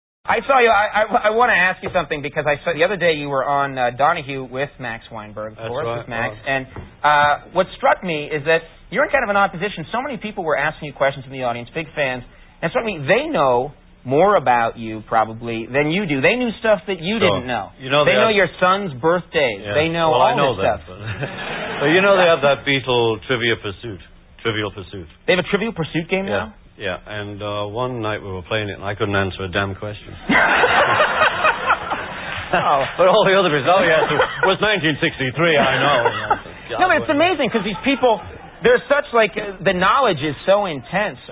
Even Ringo twisted the dagger on Late Night With Conan O'Brien (Jul 13 1995).